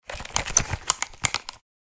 Free Leisure Sound Effects.